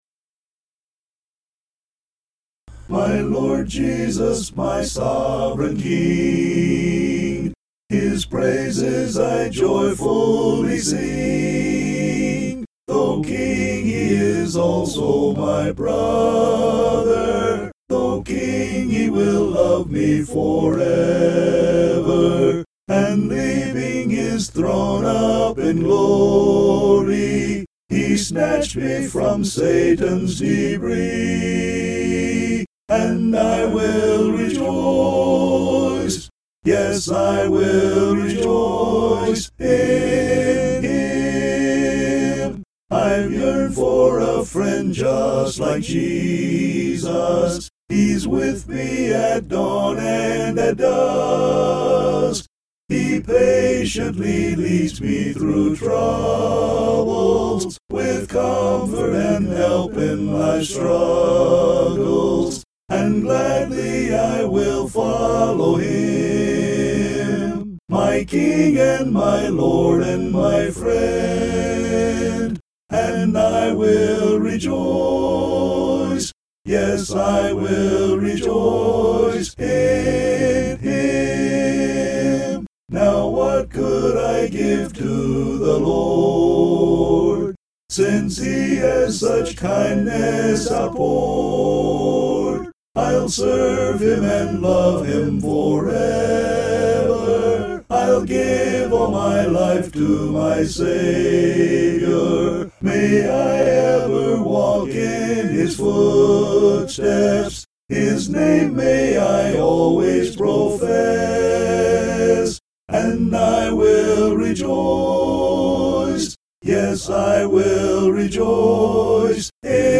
(An original hymn)